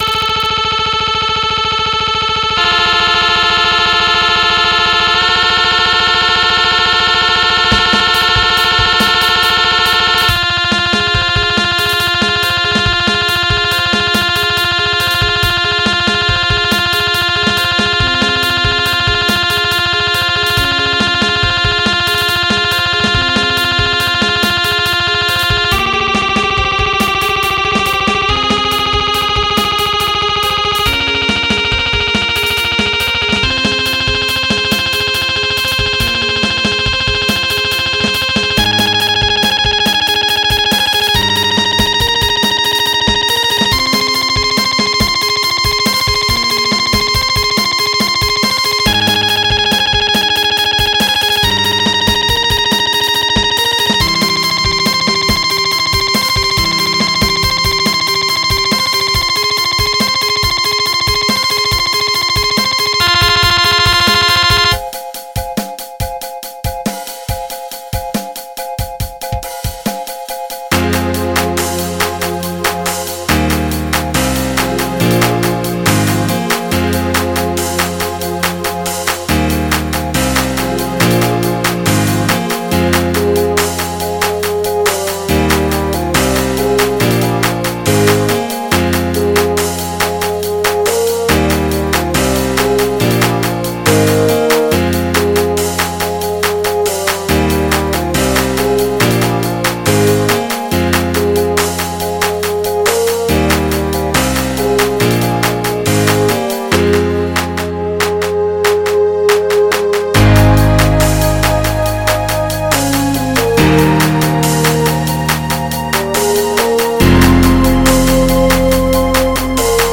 MIDI 109.9 KB MP3 (Converted)